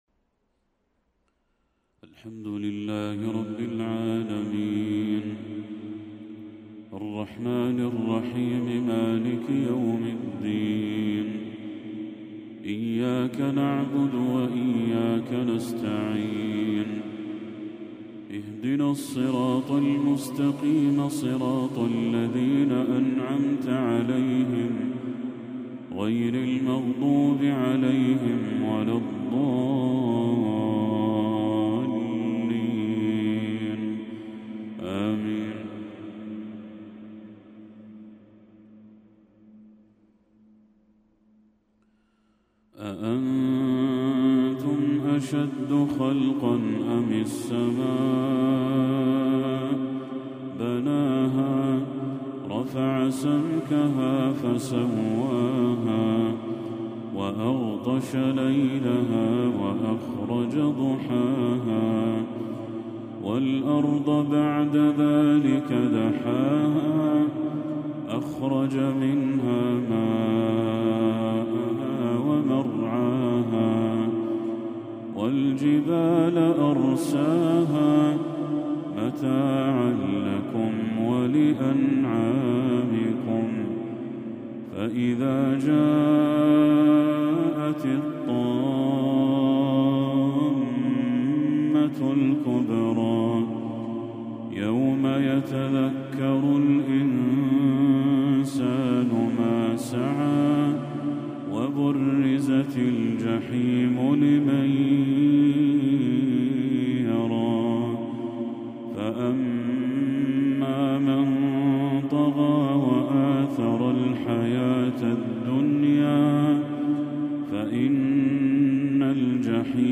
تلاوة هادئة لخواتيم سورة النازعات للشيخ بدر التركي | مغرب 26 ربيع الأول 1446هـ > 1446هـ > تلاوات الشيخ بدر التركي > المزيد - تلاوات الحرمين